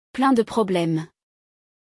Lembrando que a pronúncia é aquela do biquinho típico francês!